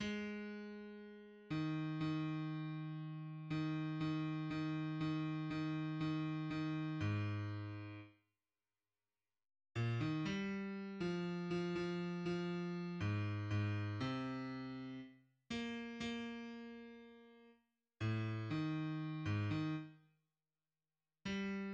{\clef bass \tempo 4 = 120 \key ees \major \numericTimeSignature \time 4/2 aes2. ees4 ees2. ees4 \time 4/2 ees4 ees ees ees ees ees \time 4/2 aes,2 r2 r4 r8 bes,8 ees8 g4. \time 3/2 f4 f8 f~ f f~ f4 aes, aes, \time 2/2 c2 r4 bes \time 3/2 bes2. r4 a,es4. aes,8 \time 4/2 ees r2 r4 g }\midi{}